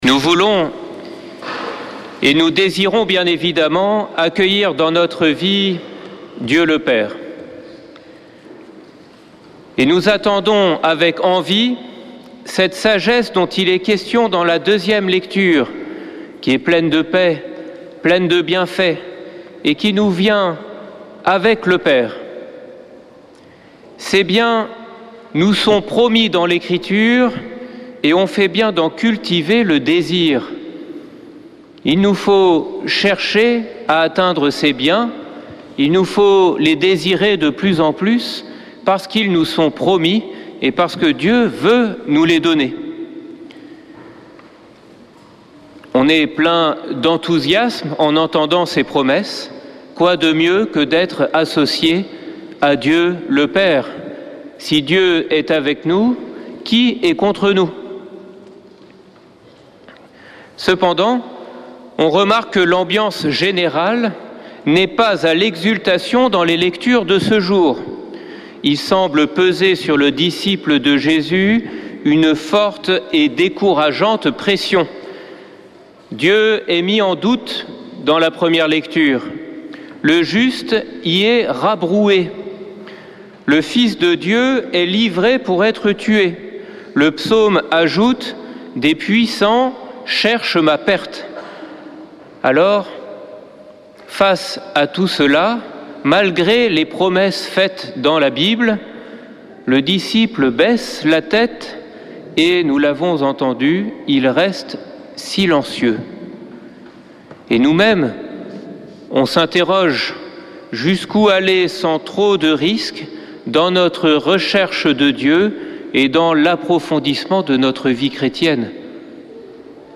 dimanche 22 septembre 2024 Messe depuis le couvent des Dominicains de Toulouse Durée 01 h 30 min
Homélie du 22 septembre